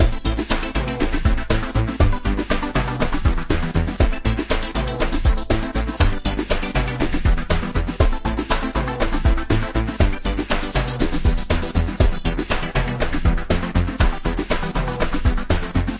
描述：具有漂移滤波器的电子回路，延迟（120 bpm）
Tag: 回路 电子 TECHNO 俱乐部 房子 舞蹈 工业